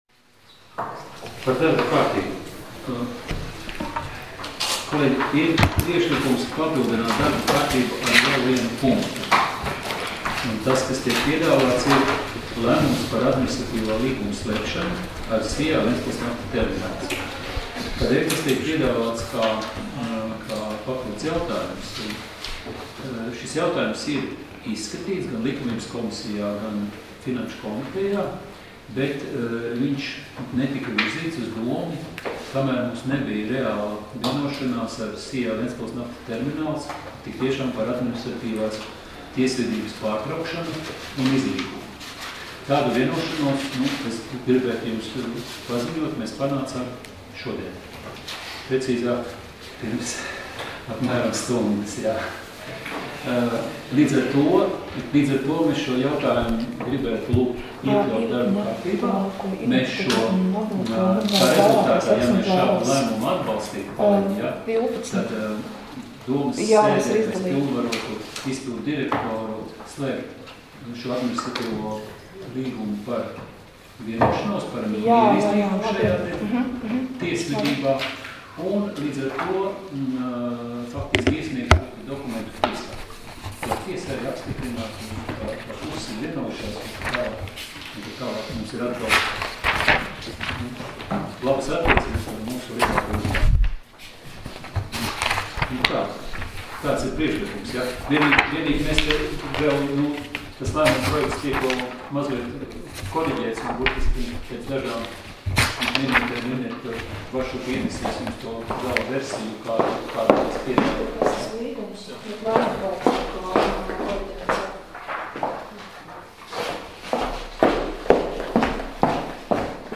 Domes sēdes 18.05.2017. audioieraksts